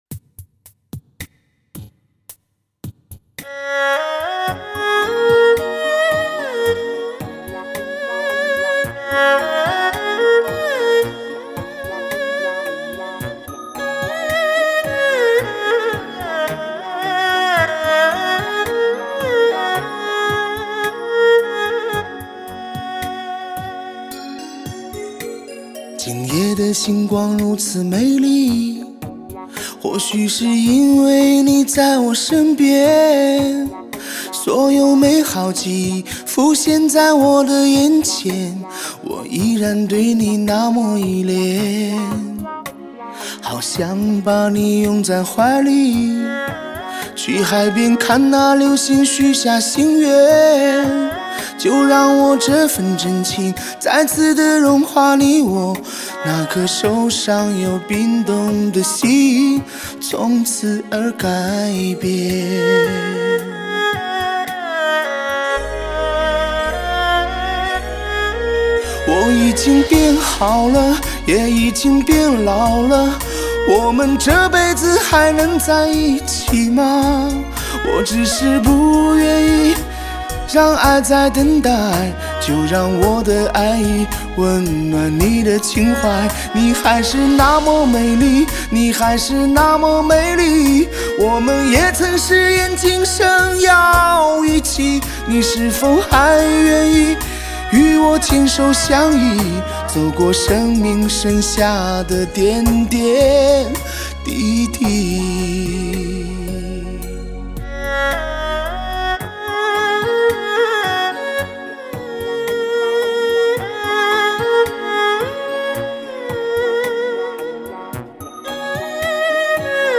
其宽广、饱满的质感，定将让你耳朵有意外的收获！